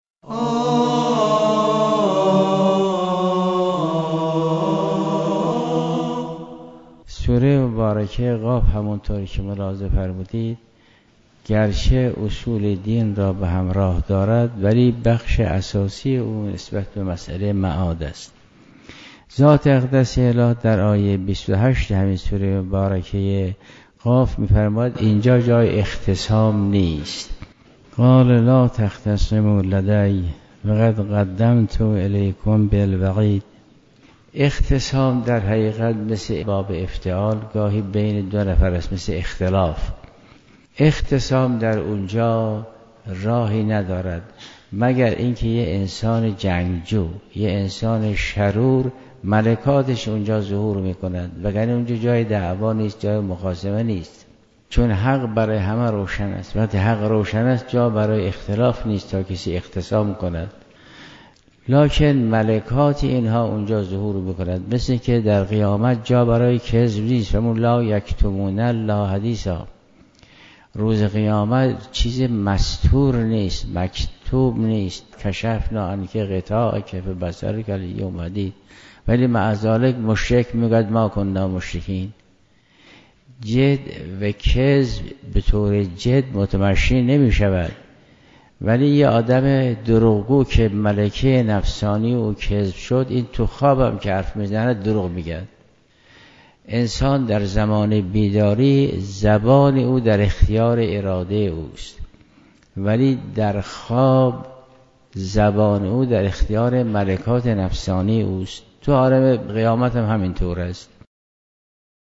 آیت‌الله جوادی آملی در جلسه تفسیر قرآن كریم با اشاره به آیه 28 سوره مباركه قاف، قیامت را جایگاه روشن‌شدن حقیقت و بی‌نیازی از هرگونه اختصام دانست.